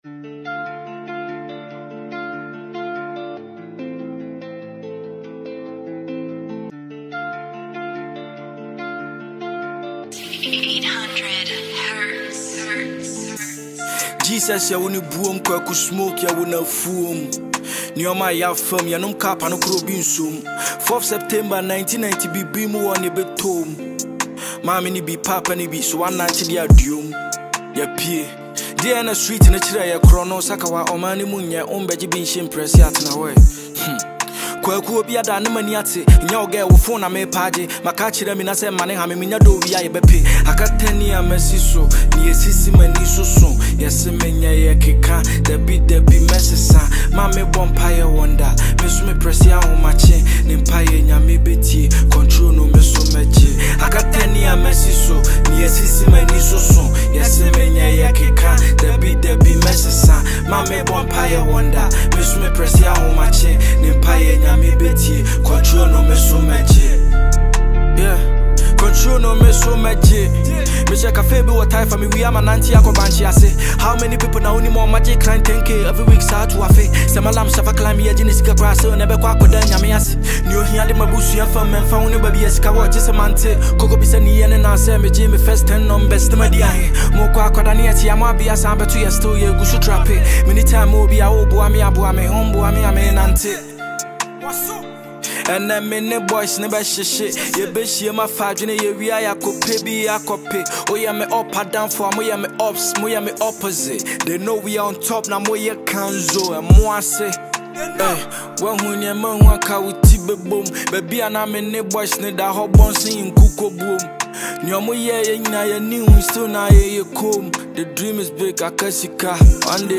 a Ghanaian hardcore rapper